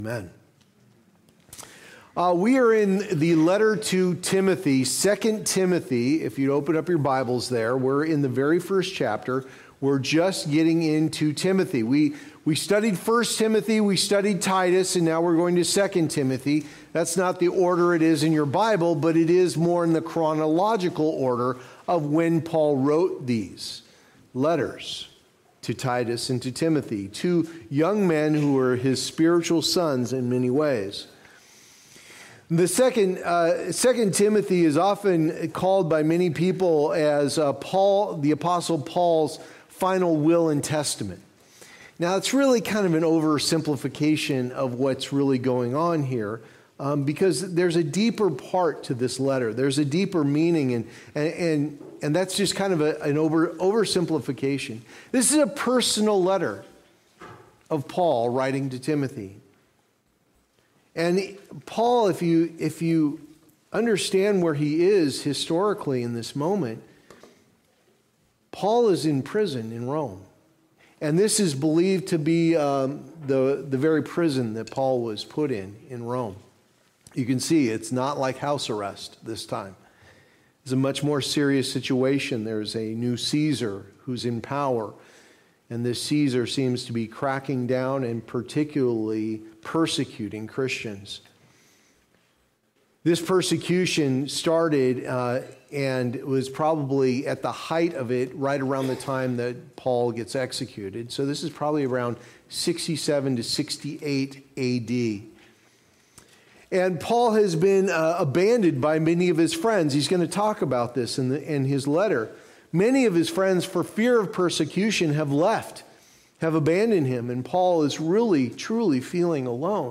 Guard the Gospel & Shepherd the Flock Passage: II Timothy 1:1-7 Services: Sunday Morning Service Download Files Notes Previous